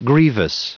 Prononciation du mot grievous en anglais (fichier audio)
Prononciation du mot : grievous